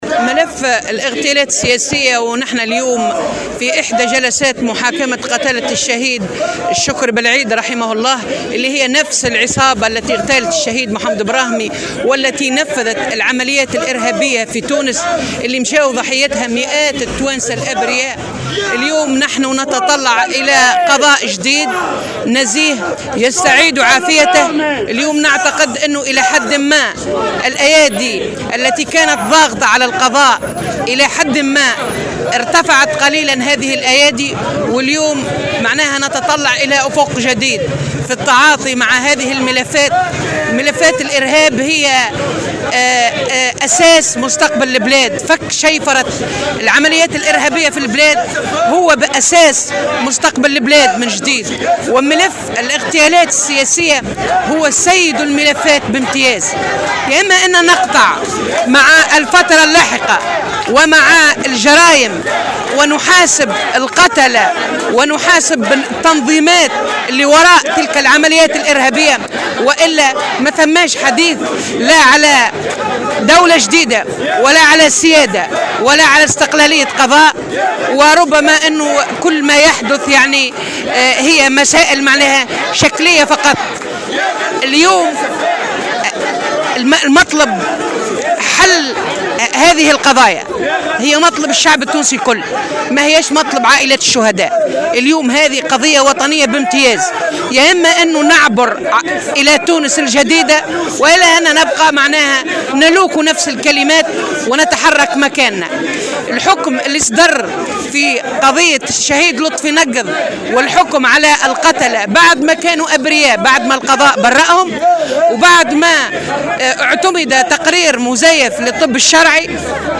وقالت في تصريح اليوم لمراسل "الجوهرة أف أم" على هامش جلسة محاكمة المتهمين في قضية اغتيال بلعيد: " الأيادي التي كانت ضاغطة على القضاء ارتفعت قليلا ونتطلّع إلى قضاء جديد نزيه وإلى تعامل جدي مع ملف الاغتيالات السياسية".